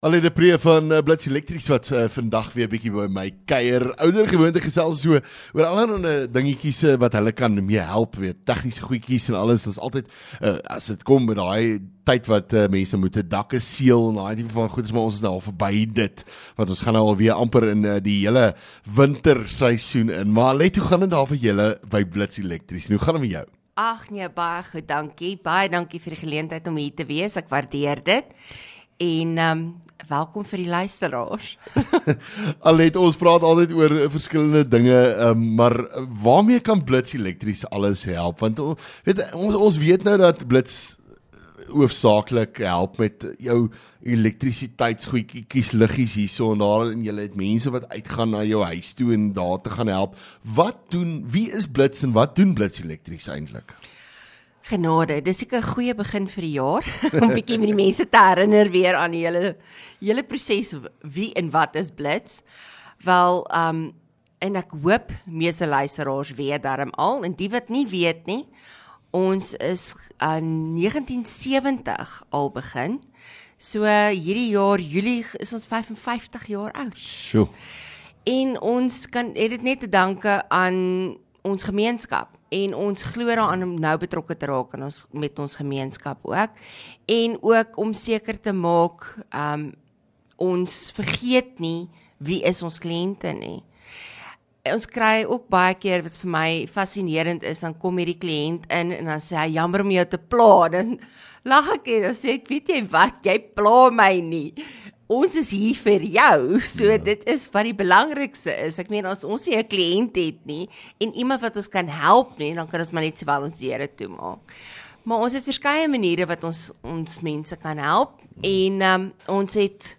LEKKER FM | Onderhoude 6 Feb Blits Elektrisiëns